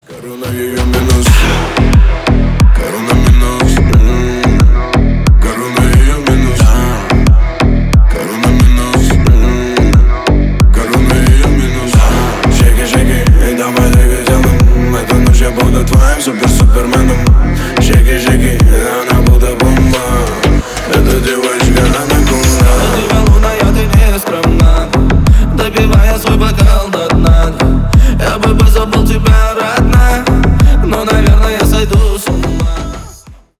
Поп Музыка # Рэп и Хип Хоп